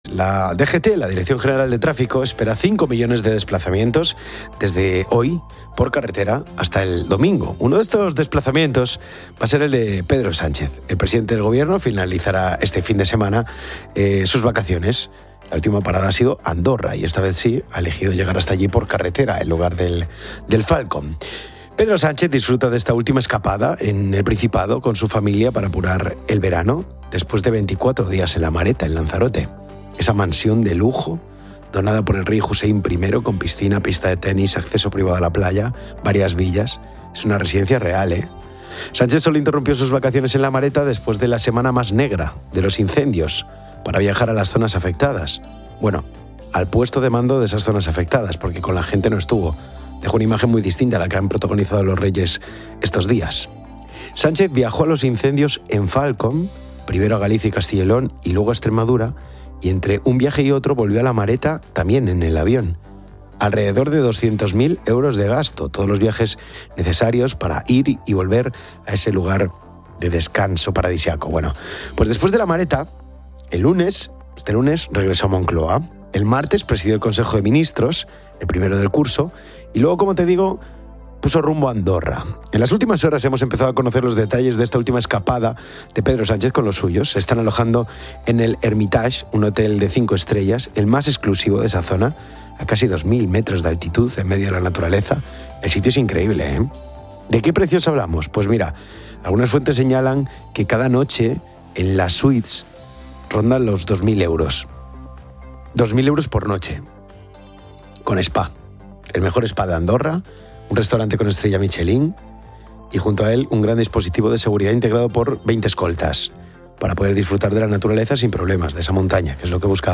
Los oyentes comparten anécdotas de sus bodas. Hoy es el Día Mundial del Videojuego; la industria supera al cine y la música.